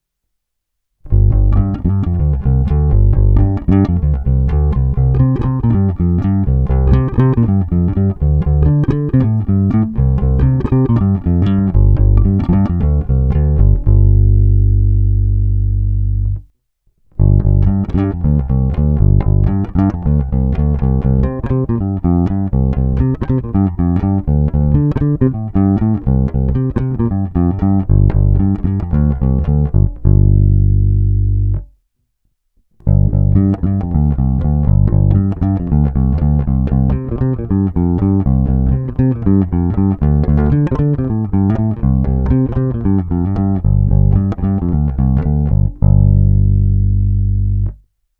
Krásně vyrovnaný zvuk mezi jednotlivými strunami.
Není-li uvedeno jinak, jsou provedeny rovnou do zvukové karty a jen normalizovány, s plně otevřenou tónovou clonou.